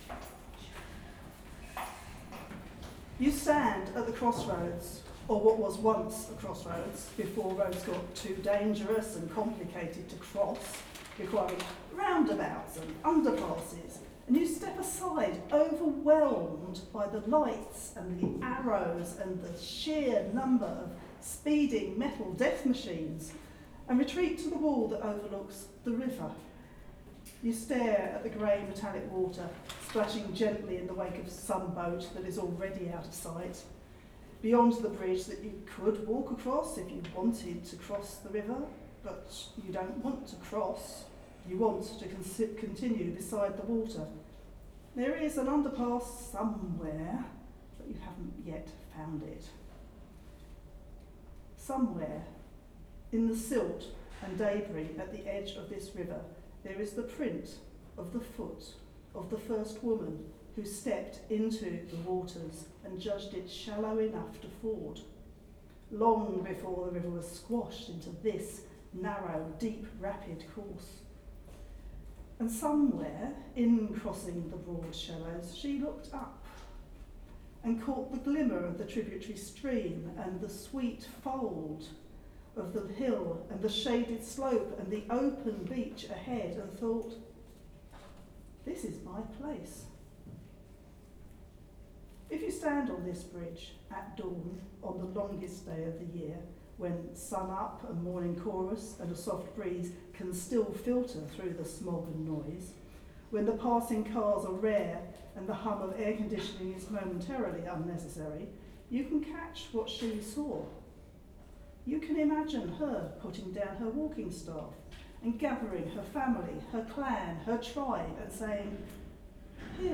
Foundation Myth is getting out and about - this is the audio recording of a reading of Story Cities we did at the Old Royal Naval College in Greenwich for Design Week. That was a lot of fun, there was a storytellers' chair!